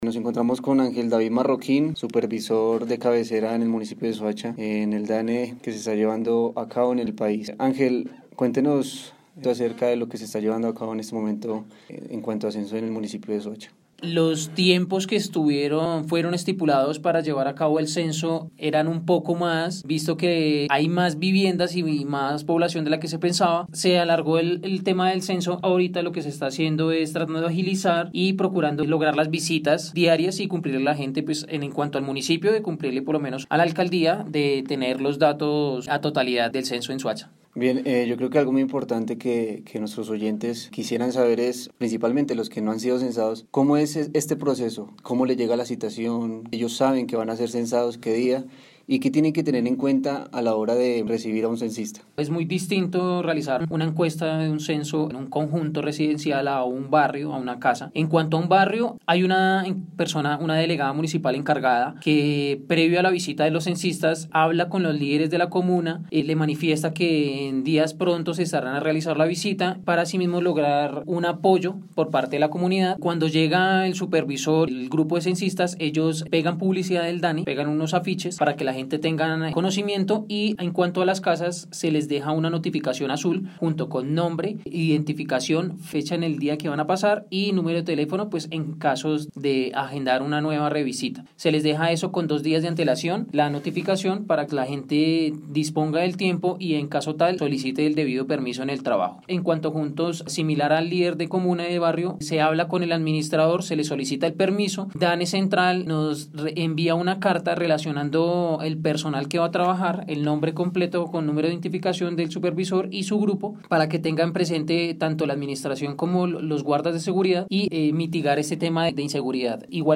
Entrevista-DANE-2.mp3